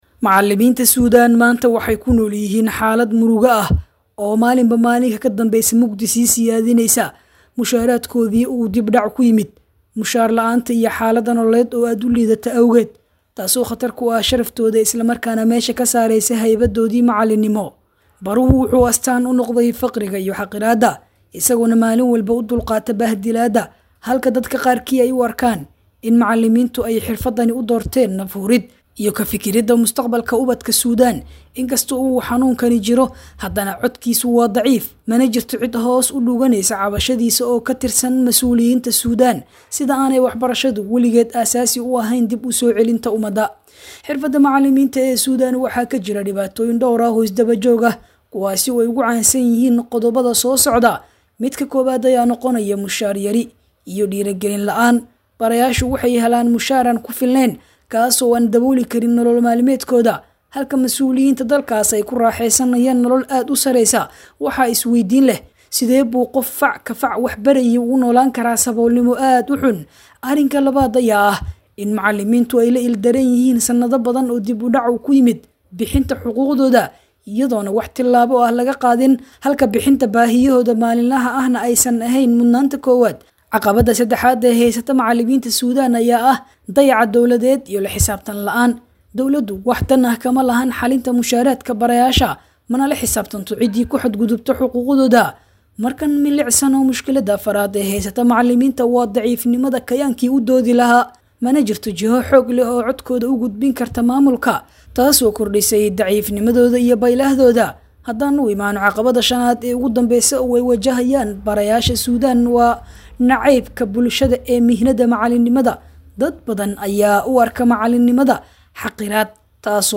Maxay Tahay Xaaladda ay Wajahayaan Barayaasha ku Nool Dalka Suudaan?[WARBIXIN]